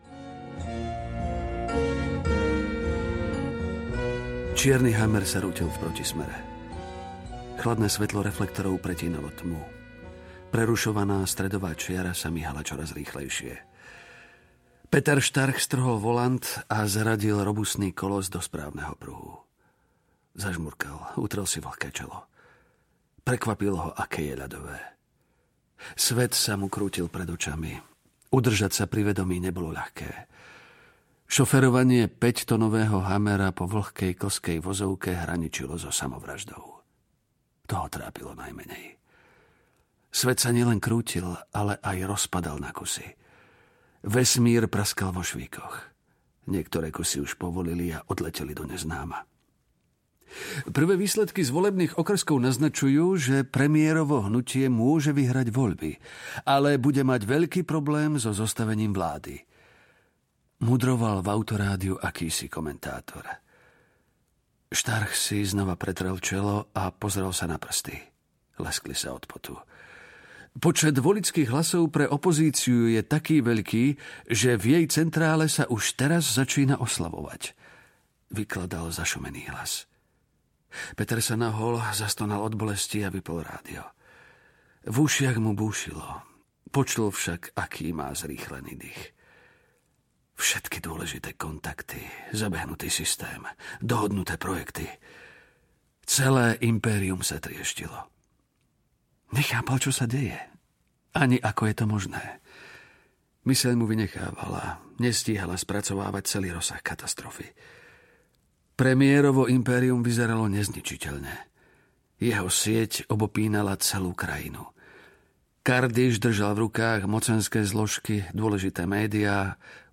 Čierny rok: Vojna mafie audiokniha
Ukázka z knihy